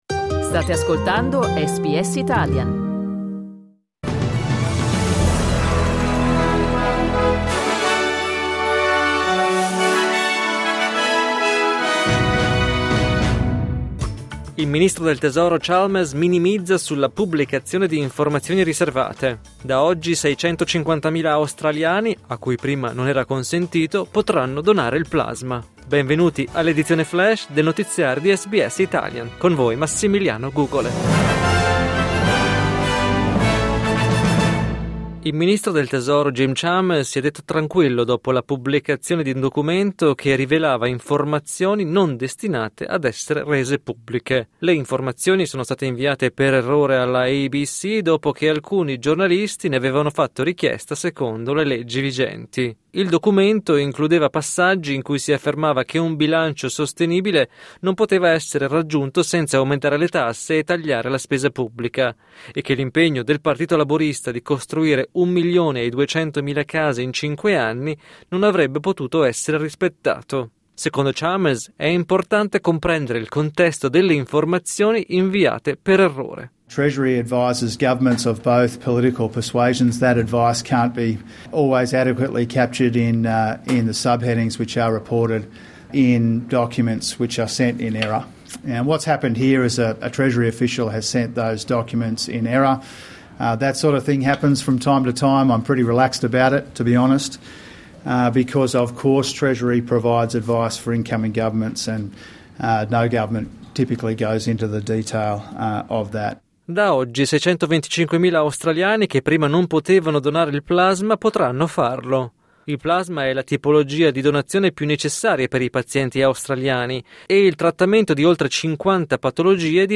News flash lunedì 14 luglio 2025